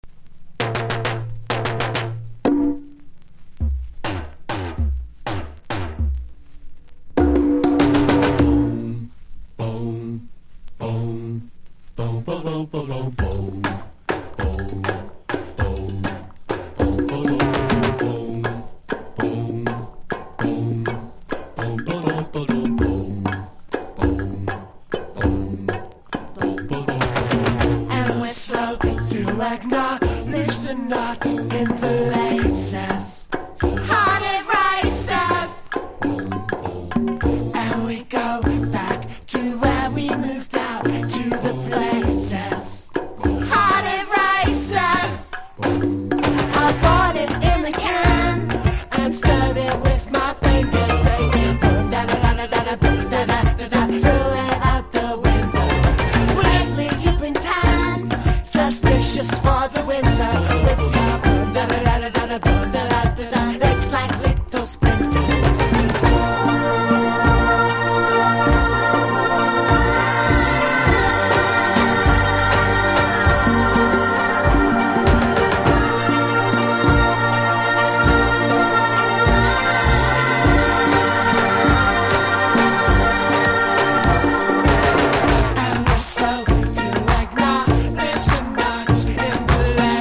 1. 00S ROCK >